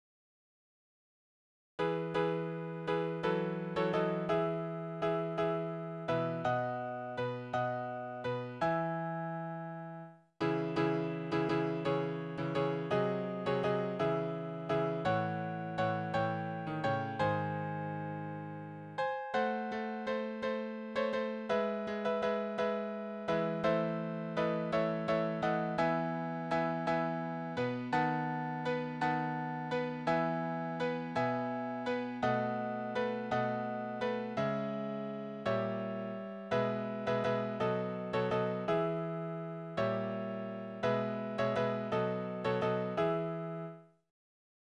Tonart: E-Dur
Taktart: 6/8
Tonumfang: kleine None
Besetzung: vokal
Anmerkung: vierstimmiger Chorsatz